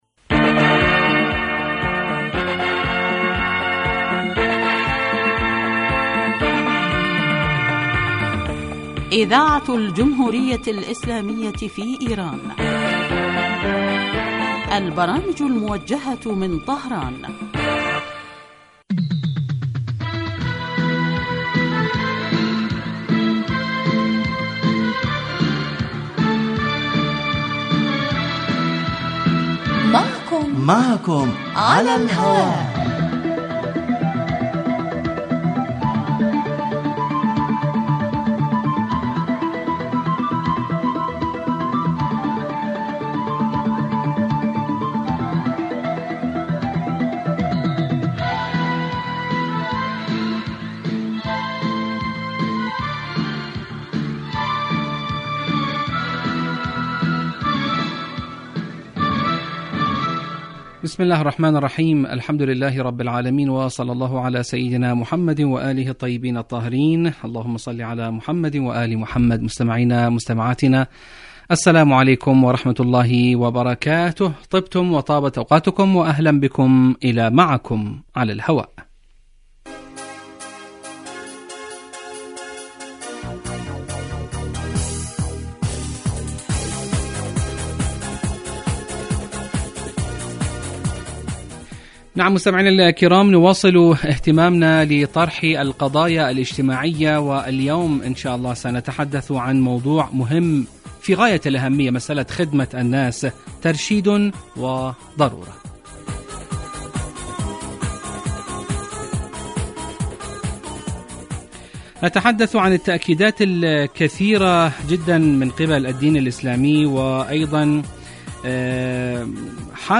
من البرامج المعنیة بتحلیل القضایا الاجتماعیة في دنیا الإسلام و العرب و من أنجحها الذي یلحظ الکثیر من سیاسات القسم الاجتماعي بصورة مباشرة علی الهواء وعبر الاستفادة من رؤی الخبراء بشان مواضیع تخص هاجس المستمعین